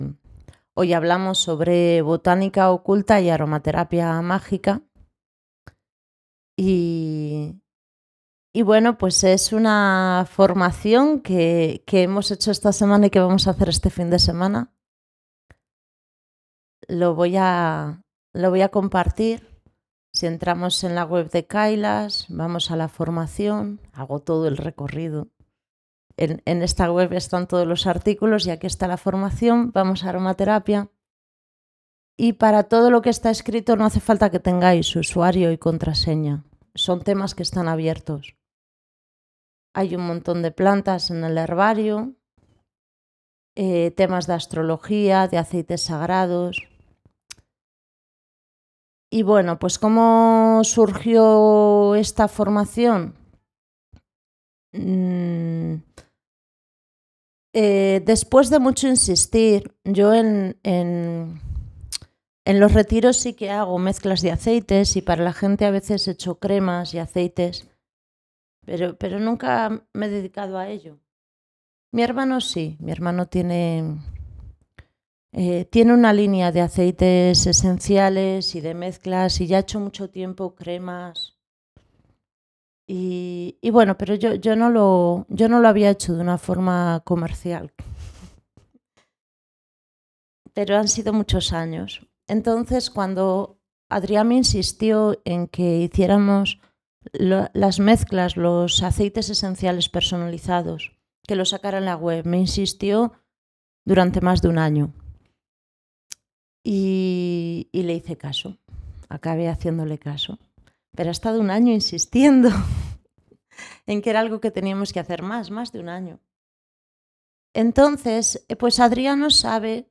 Charla presentación de la formación de Aromaterapia mágica y botánica oculta.